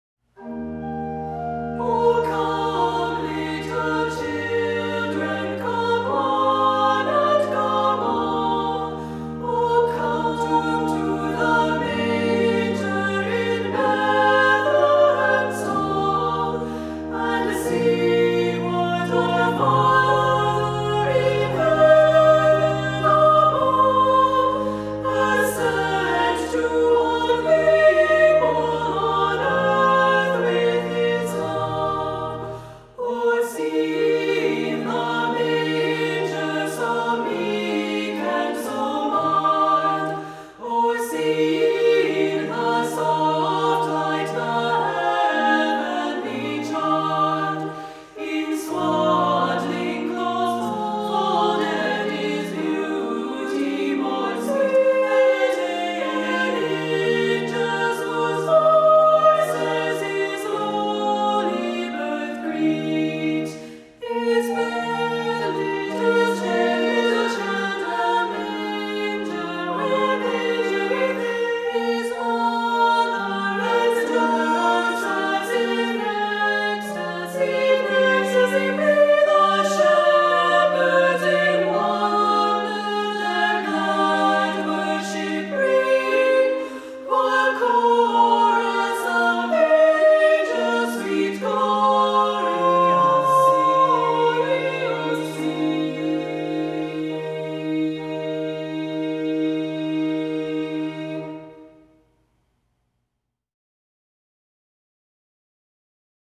Voicing: 2-part Choir